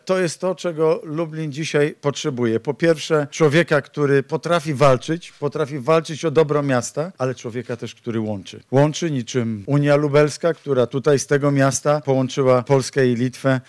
Kandydata przedstawił wiceprezes Prawa i Sprawiedliwości Mateusz Morawiecki, który otworzył prezentację w Lubelskim Centrum Konferencyjnym: